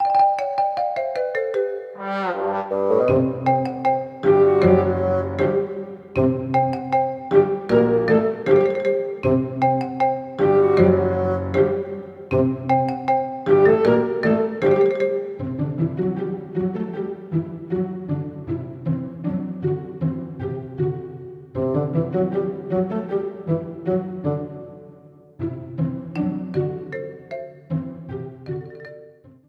Ripped from the game
clipped to 30 seconds and applied fade-out
Fair use music sample